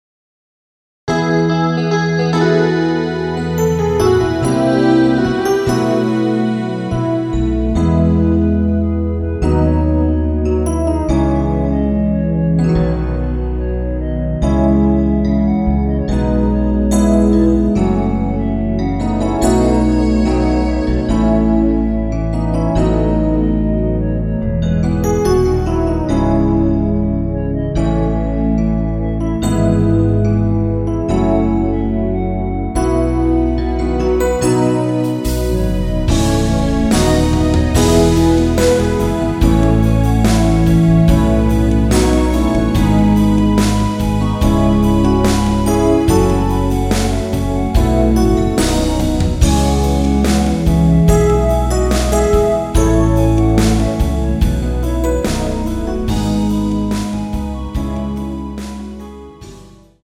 페이드 아웃곡이라 라이브하기좋게 엔딩을 만들어 놓았습니다.
원키에서(-2)내린 멜로디 포함된 MR입니다.
앞부분30초, 뒷부분30초씩 편집해서 올려 드리고 있습니다.
(멜로디 MR)은 가이드 멜로디가 포함된 MR 입니다.